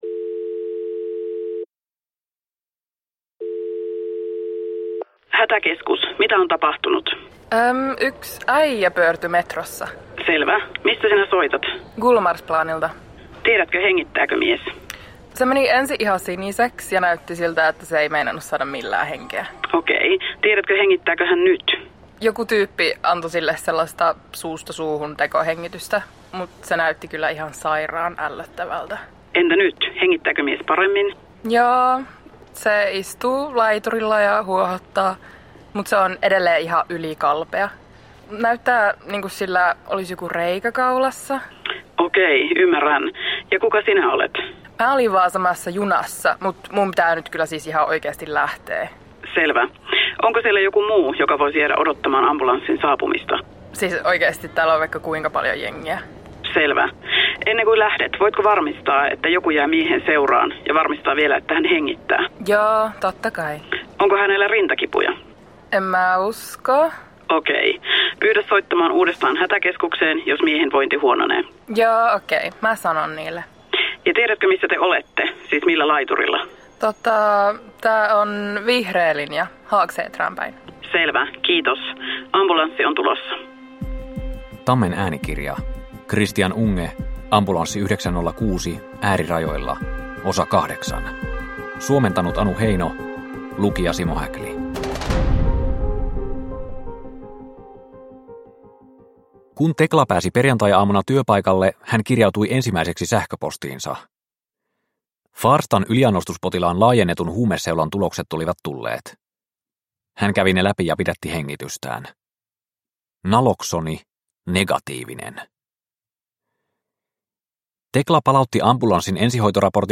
Ambulanssi 906 Osa 8 – Ljudbok – Laddas ner